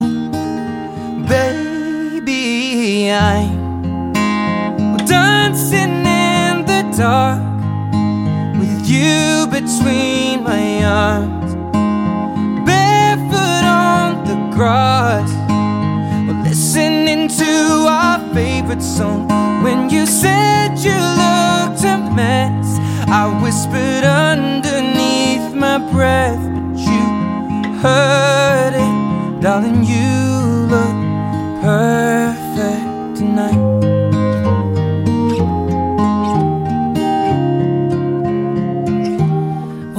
акустика , романтические
гитара
поп , баллады